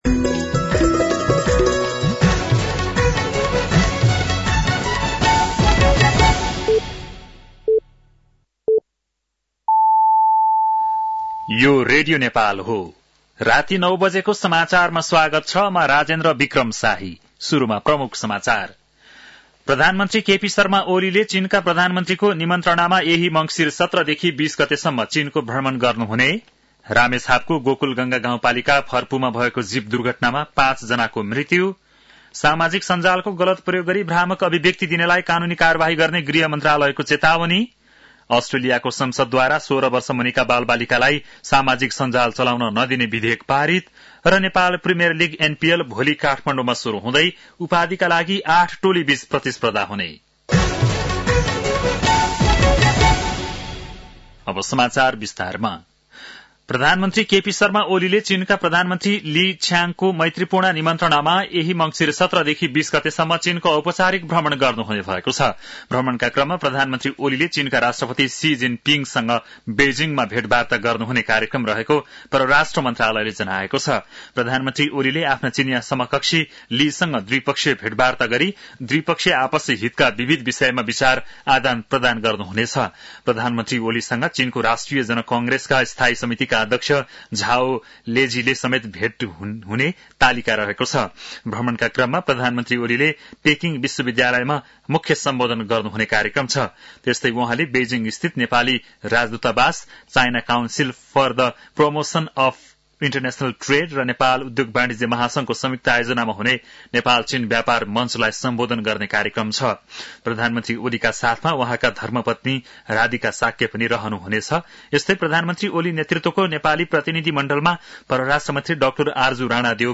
An online outlet of Nepal's national radio broadcaster
बेलुकी ९ बजेको नेपाली समाचार : १५ मंसिर , २०८१
9-PM-Nepali-News-8-14.mp3